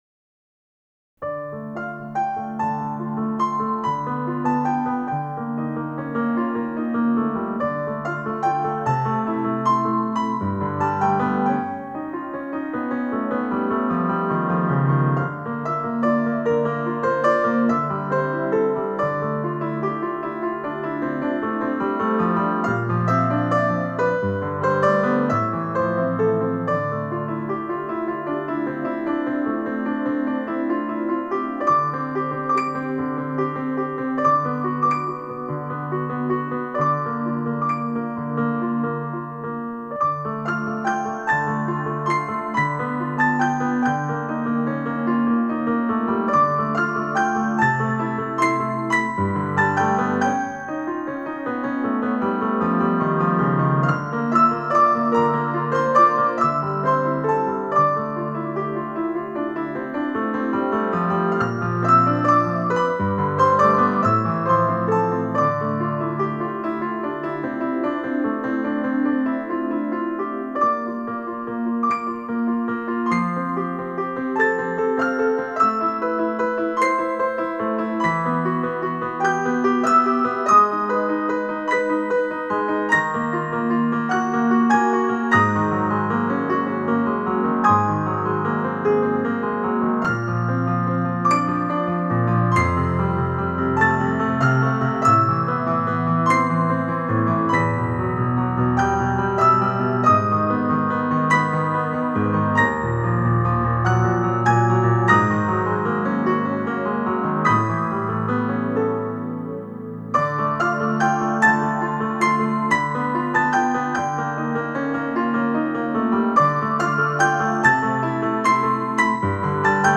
Das Thema des Wasserfalls - klare und reine Melodie am Anfang - klingt noch sehr beruhigend und harmonisch. Auf der anderen Seite ist das Thema des silbernen Mondes geheimnisvoll und mysteriös. Man hört den spielerischen Charakter des Wassers, die brillanten Wasserspritzer und die treppenartigen Kaskaden.
Wild und wuchtig brausen "dämonischen Strömungen" und die prächtige Flut fällt in die dunkle Tiefe.